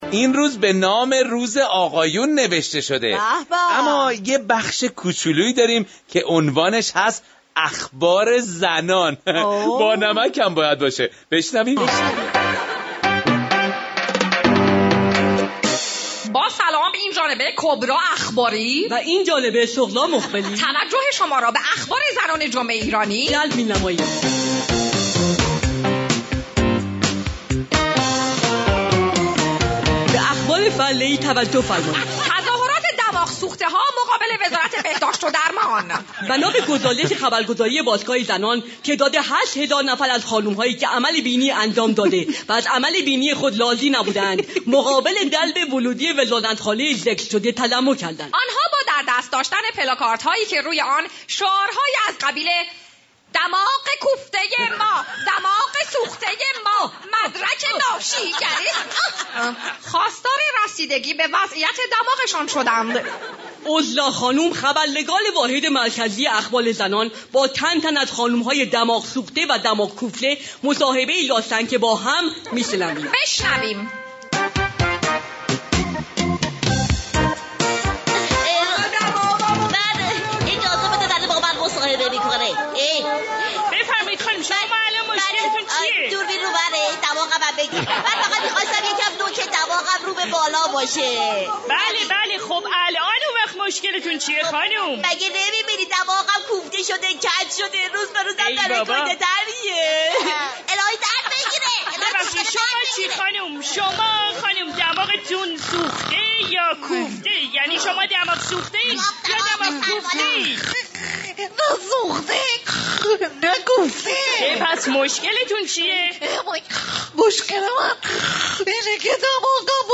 برنامه طنز جمعه ایرانی هر جمعه ساعت 9 تا 11:30 از رادیو ایران